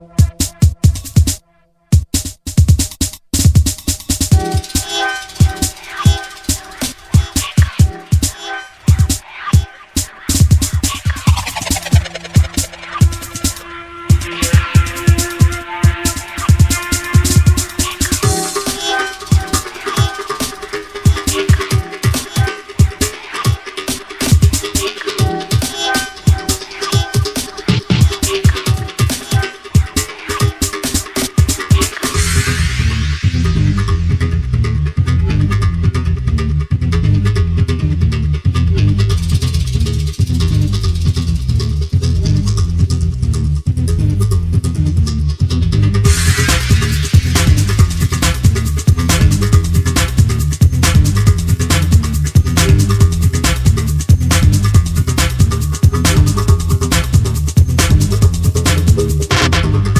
Электронная
Дебютный альбом легенд английского прогрессив-хауса.